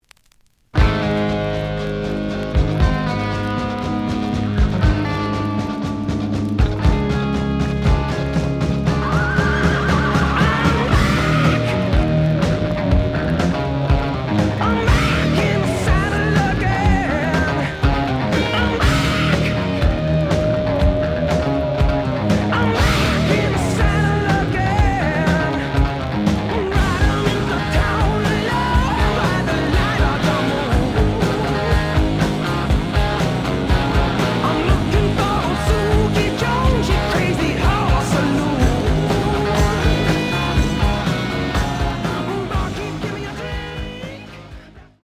試聴は実際のレコードから録音しています。
●Genre: Rock / Pop
盤に若干の歪み。